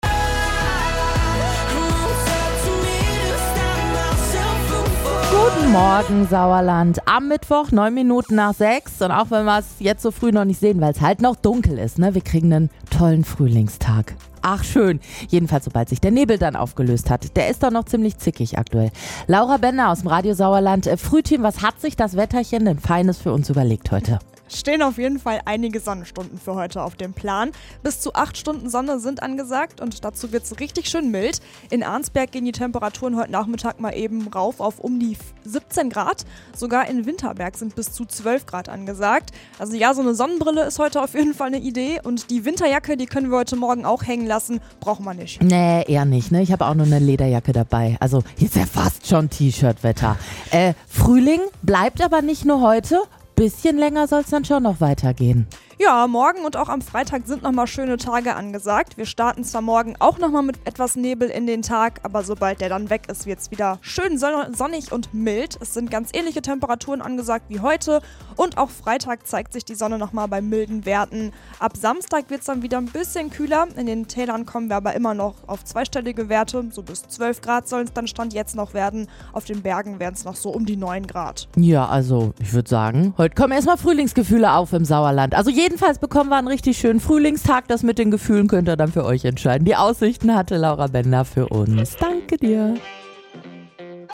Wir wollten von euch wissen, was ihr bei diesem Wetter macht! Eure Antworten hört ihr hier.